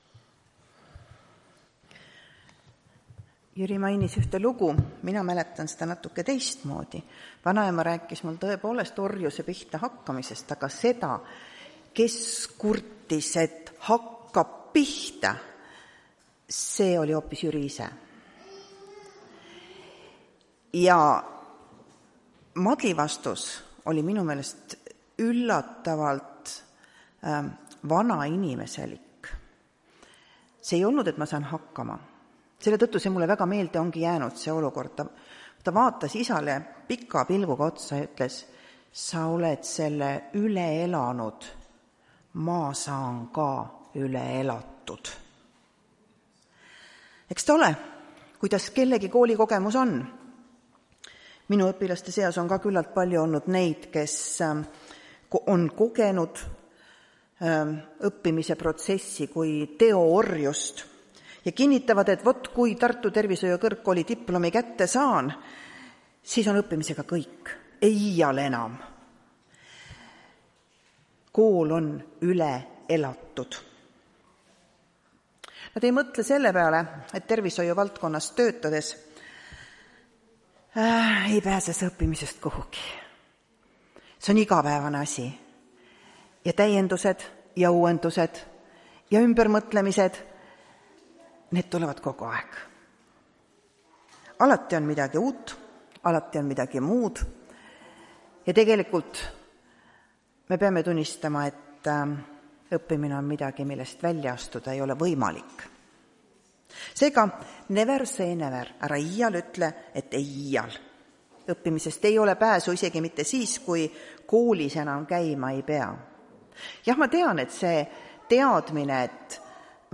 Tartu adventkoguduse 31.08.2024 hommikuse teenistuse jutluse helisalvestis.